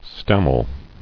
[stam·mel]